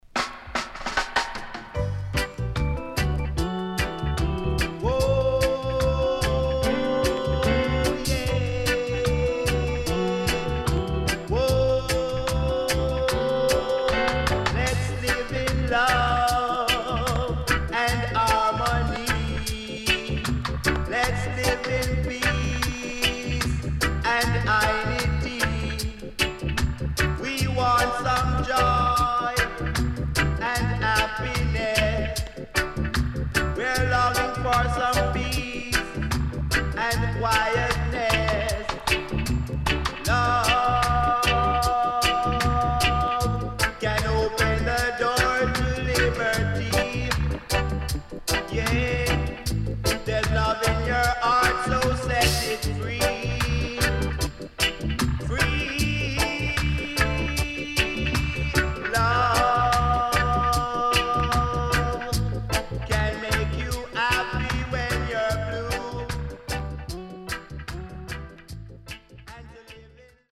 聴きやすい曲から渋めのルーツまでコンパイルしたナイスアルバム.良曲多数収録
SIDE A:少しチリノイズ入りますが良好です。
SIDE B:少しチリノイズ入りますが良好です。